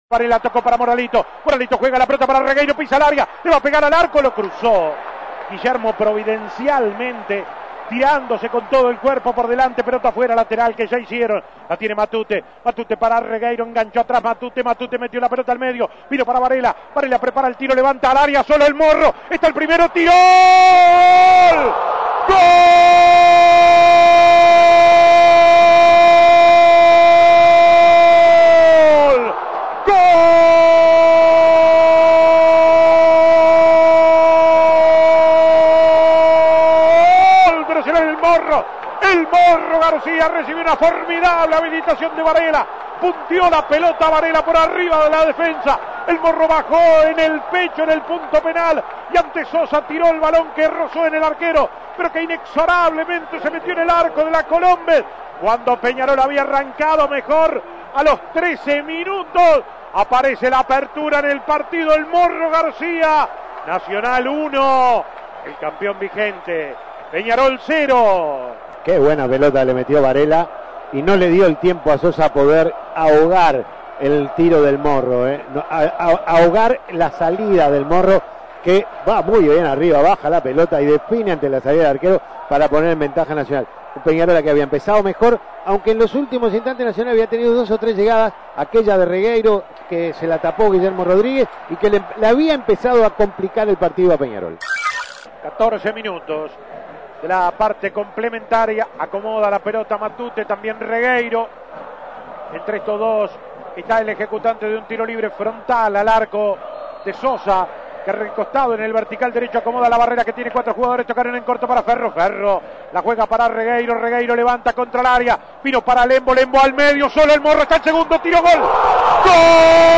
Escuche los goles del Clásico
Goles y comentarios Escuche los goles del Clásico Imprimir A- A A+ Nacional le ganó a Peñarol 2-0 y forzó dos partidos más para definir al campeón uruguayo.